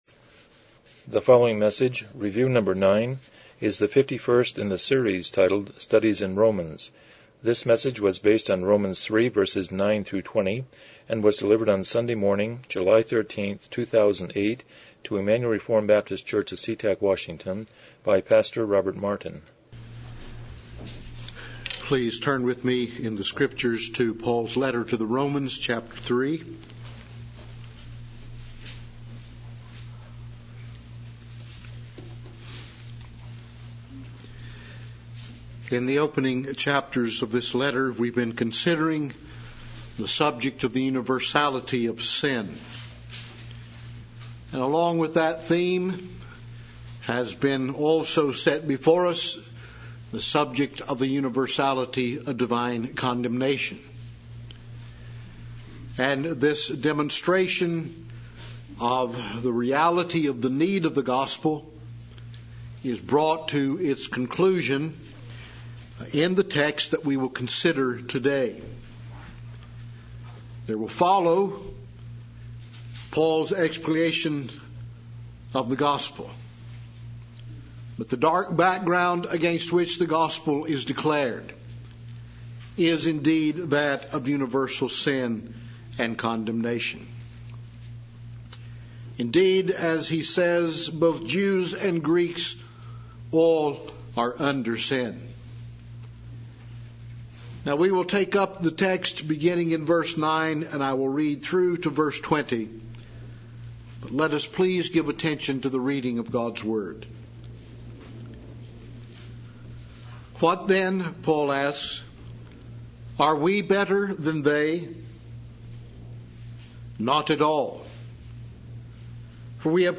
Passage: Romans 3:9-20 Service Type: Morning Worship